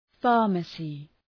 Προφορά
{‘fɑ:rməsı}